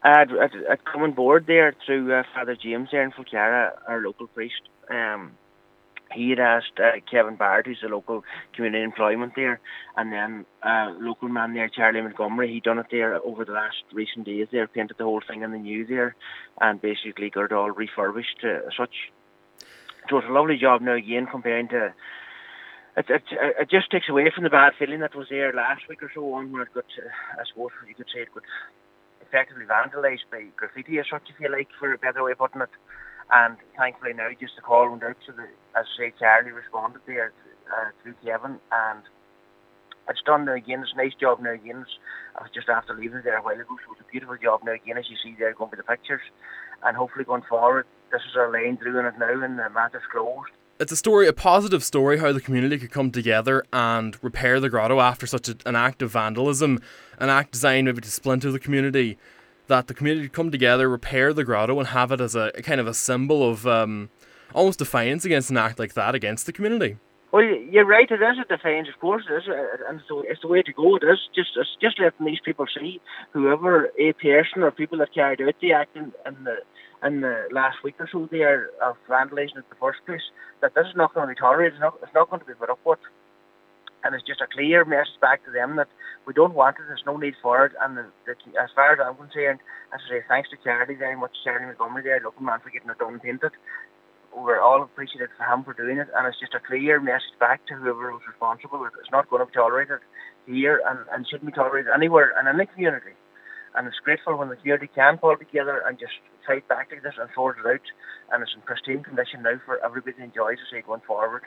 Local Cllr Michael McClafferty told Highland Radio News that the community coming together to repair the grotto shows that such acts will not be tolerated in the local community…